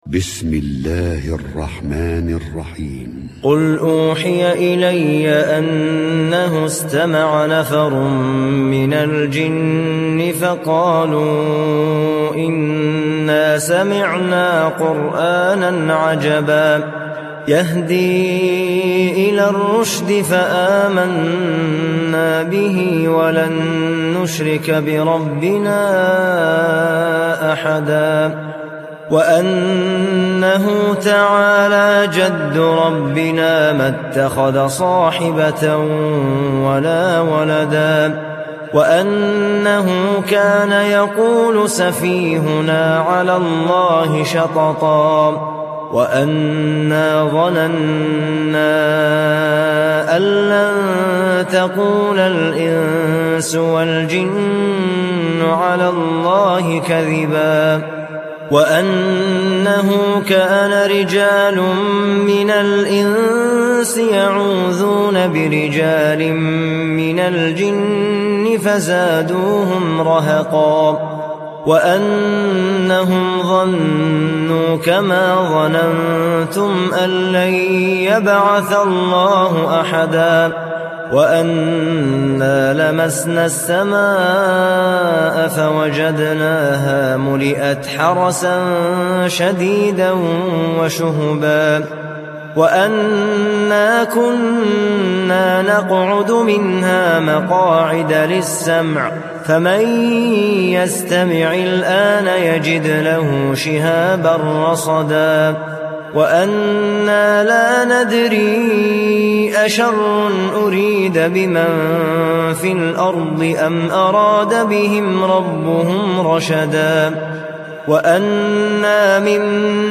Audio Quran Tarteel Recitation
Surah Repeating تكرار السورة Download Surah حمّل السورة Reciting Murattalah Audio for 72. Surah Al-Jinn سورة الجن N.B *Surah Includes Al-Basmalah Reciters Sequents تتابع التلاوات Reciters Repeats تكرار التلاوات